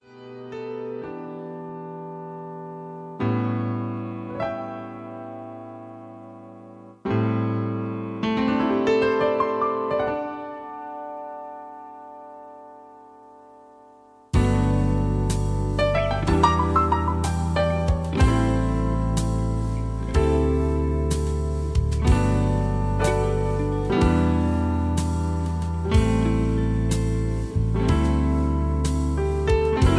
(Version-2, Key-G-Ab) Karaoke MP3 Backing Tracks
Just Plain & Simply "GREAT MUSIC" (No Lyrics).
karaoke mp3 tracks